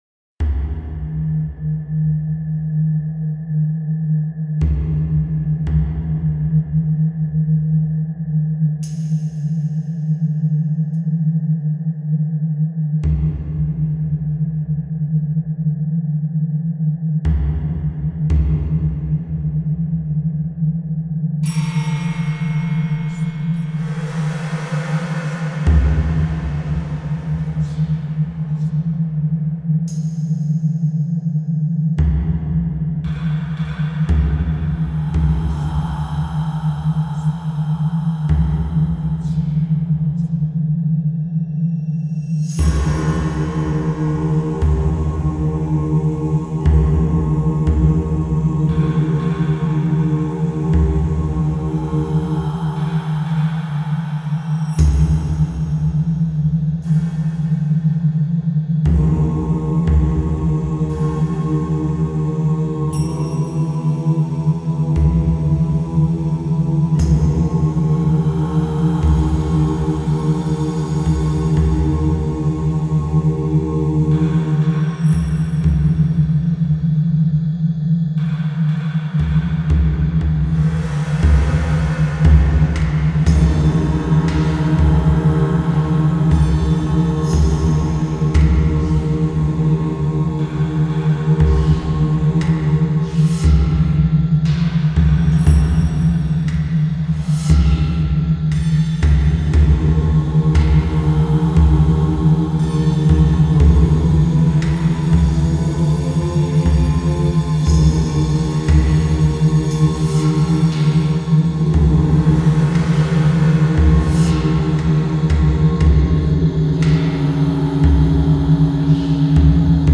怪しく鳴り響く和太鼓や鈴、人々が囁くような呪詛も背景に薄らと入っています。
呪詛の囁き入りの儀式をイメージした和風ホラー曲です。
♩=free
太鼓,シンセパッド,クワイア,パーカッション etc